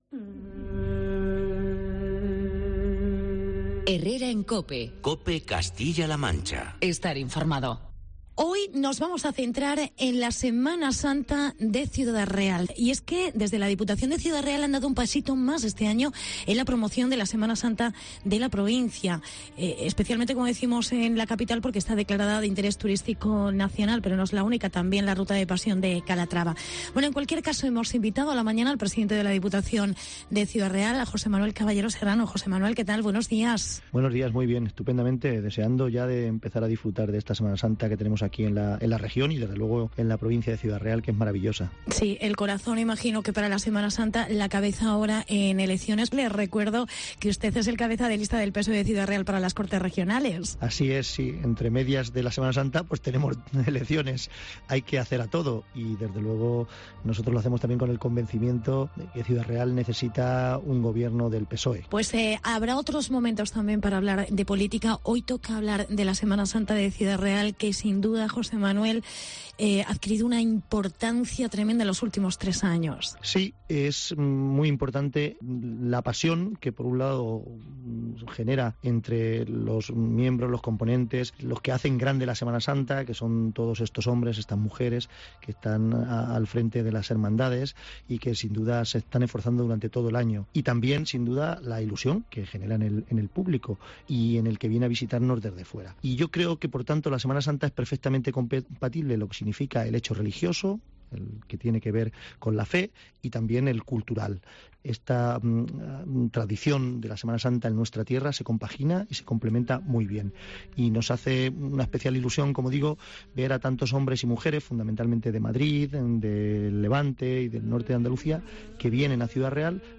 Semana Santa en Ciudad Real y provincia. Entrevista con José Manuel Caballero. Pte Diputación